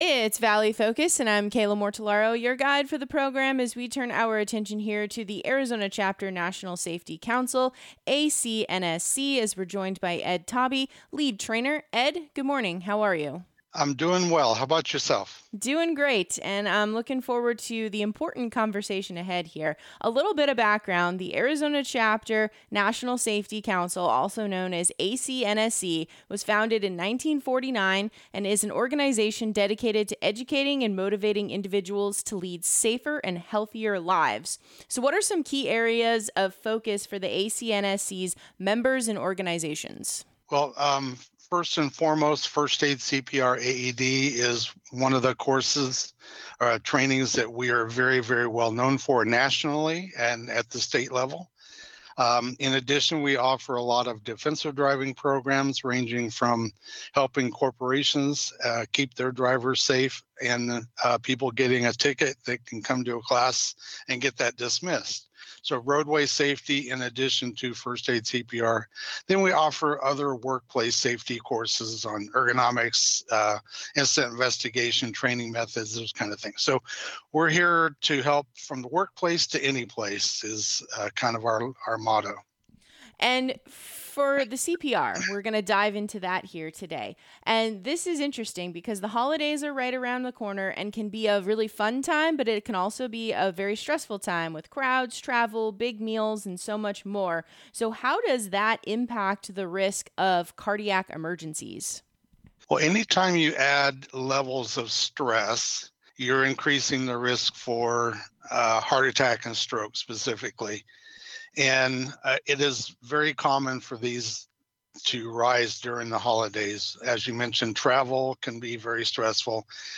Valley Focus is a radio program produced by Hubbard Radio Phoenix that highlights the work of nonprofits across the state. The program airs on KDUS AM 1060.